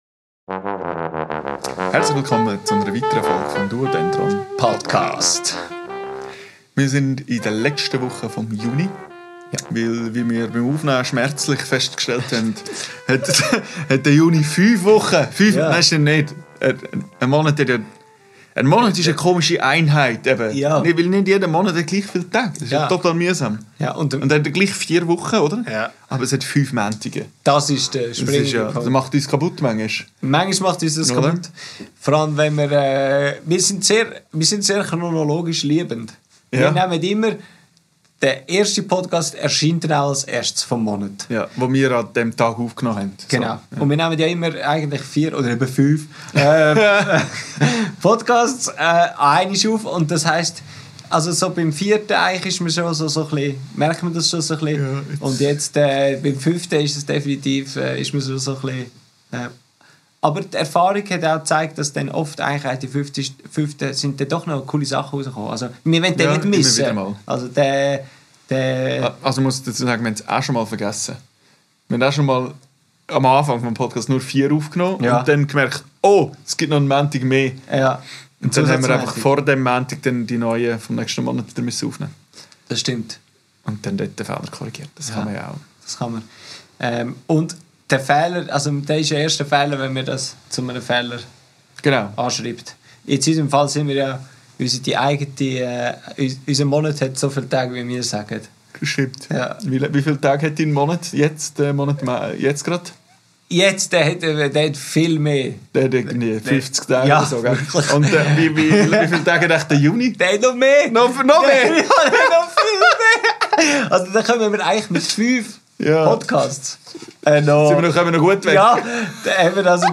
Wir testen eine neue Improvisations-Idee.
Aufgenommen am 22.05.2025 im Atelier